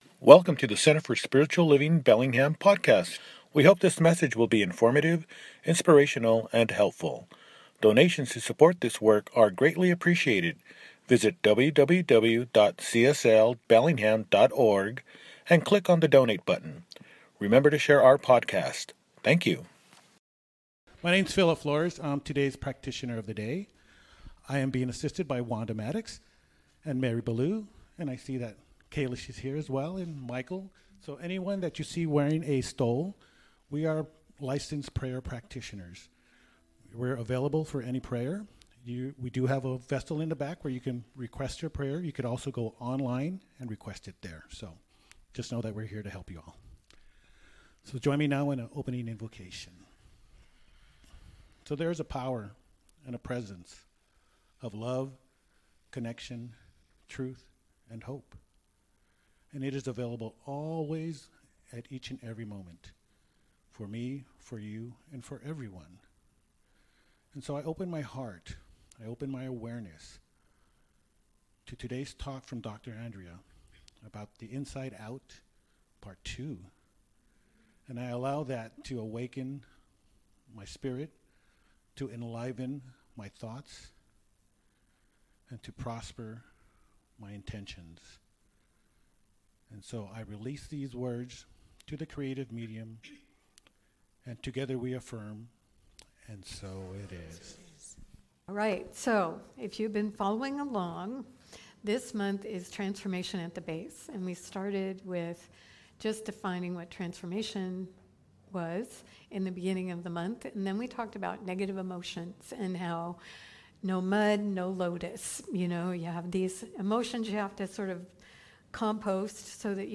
Inside Out 2 – Celebration Service